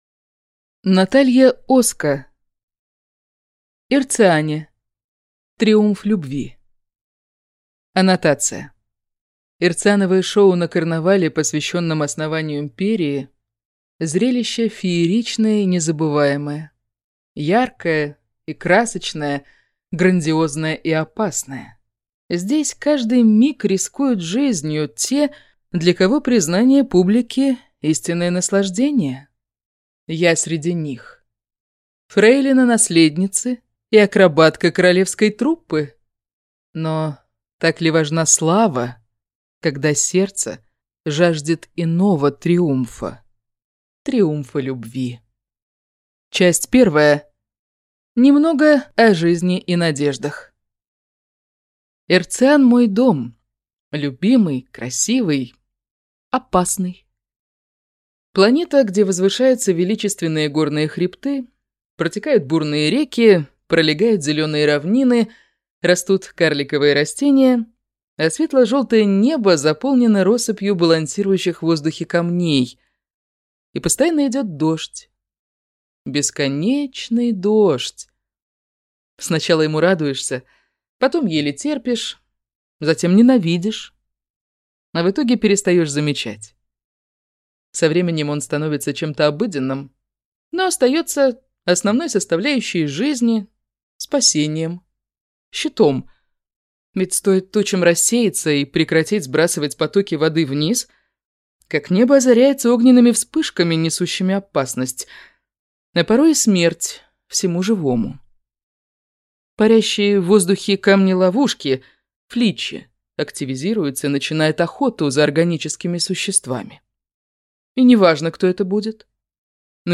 Аудиокнига Эрциане. Триумф любви | Библиотека аудиокниг